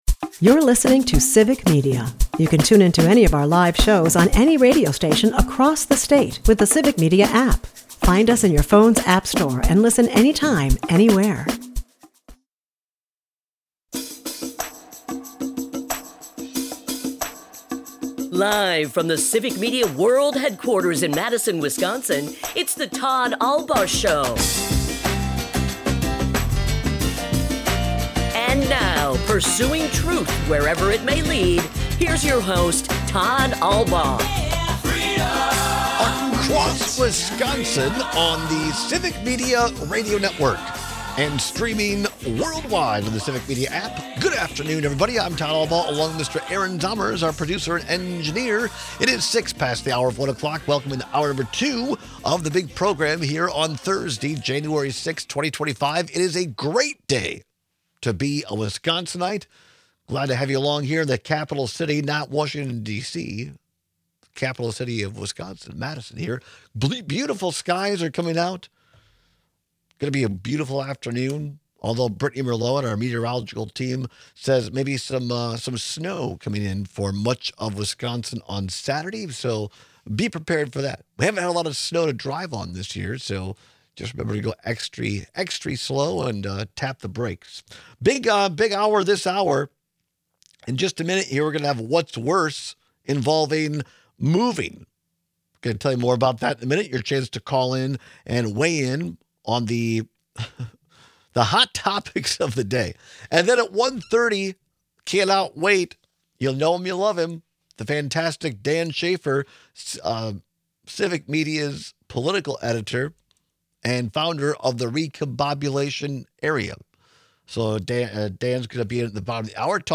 Broadcasts live 12 - 2p across Wisconsin.
We kick off today’s second hour with some audio from Democrats Brian Schiff and Elizabeth Warren about why Elon Musk’s FBI raids and Treasury information breaches matter to the average American.